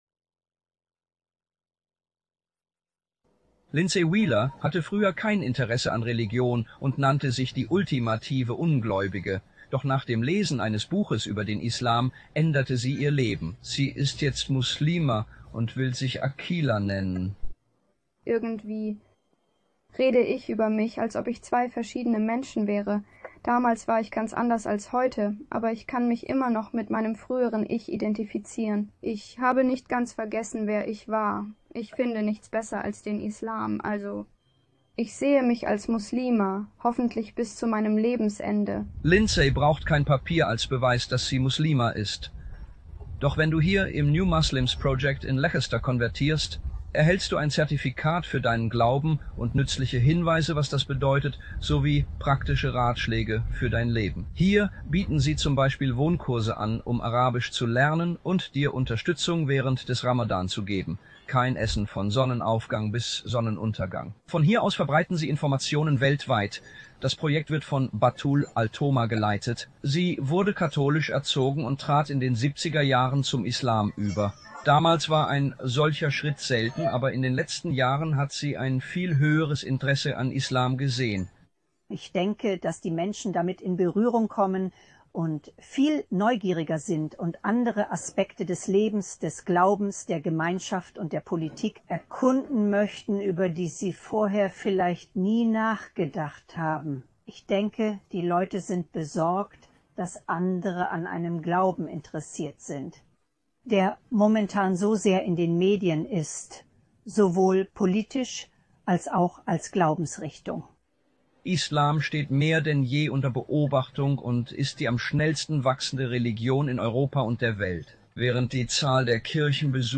Beschreibung: In dieser Nachrichtensendung von Five News berichten Reporter, wie der Islam zur am schnellsten wachsenden Religion im Vereinigten Königreich und ganz Europa wurde.